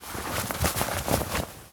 foley_cloth_light_fast_movement_05.wav